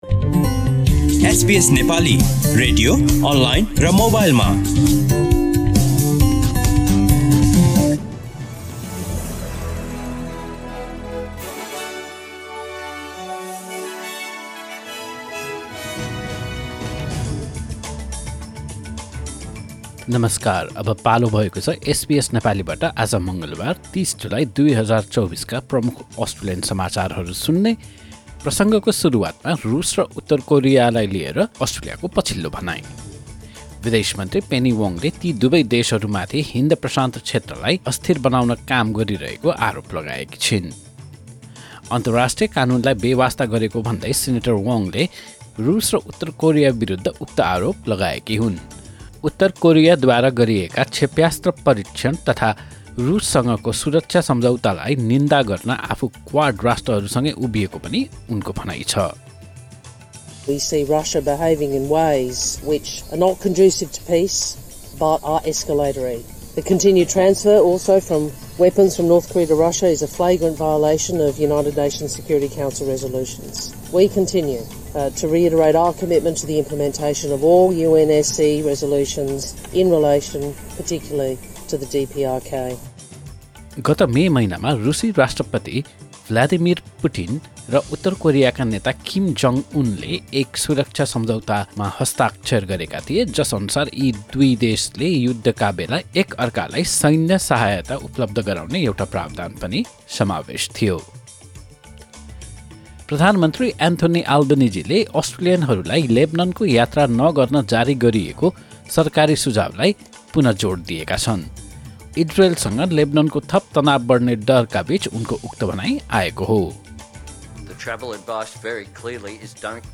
SBS Nepali Australian News Headlines: Tuesday, 30 July 2024